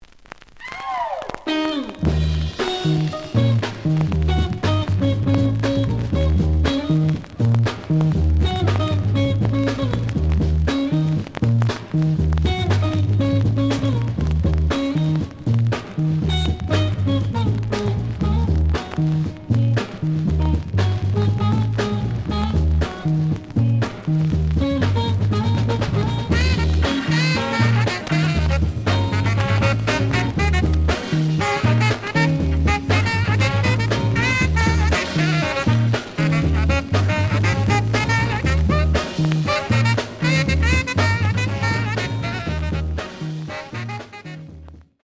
The audio sample is recorded from the actual item.
●Genre: Funk, 60's Funk
Noticeable noise on middle of A side.